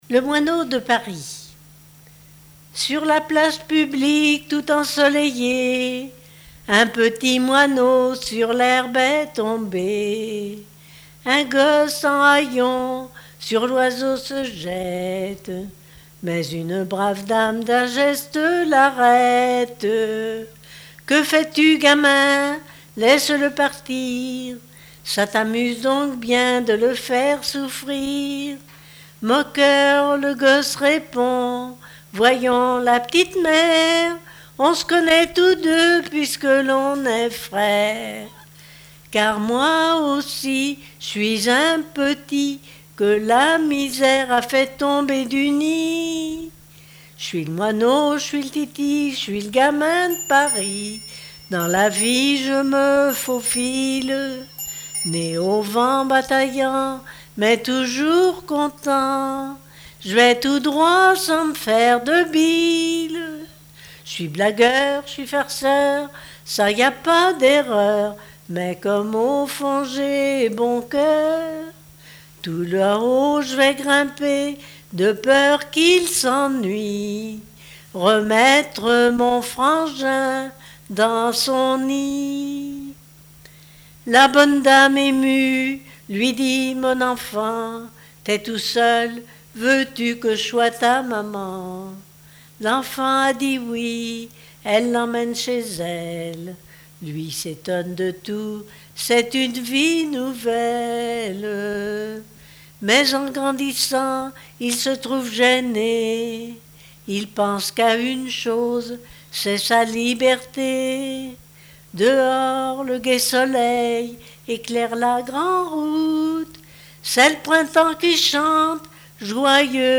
chanson de variété
Genre strophique
Répertoire de chansons de variété
Pièce musicale inédite